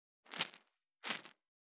３７３薬を振る,薬瓶,薬瓶振る,シャカシャカ,カラカラ,チャプチャプ,コロコロ,シャラシャラ,
効果音